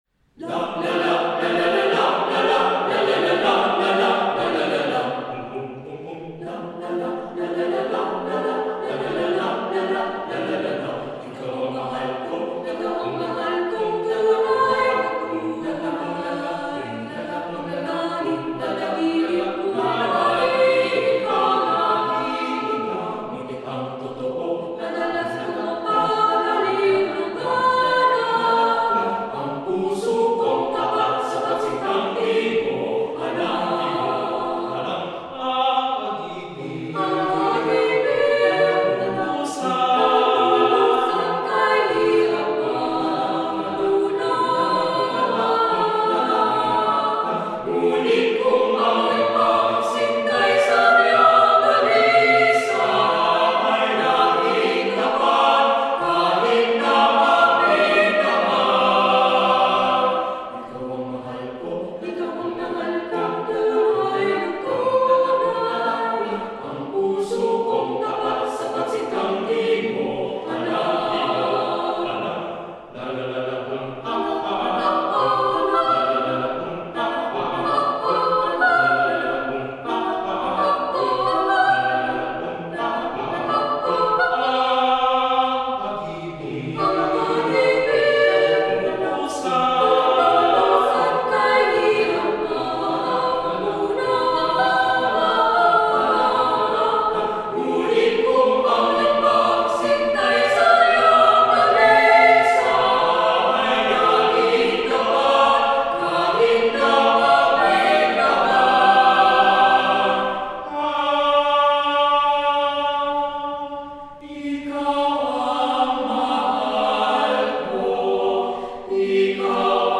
Composer: Filipino Folk Song
Voicing: SATB a cappella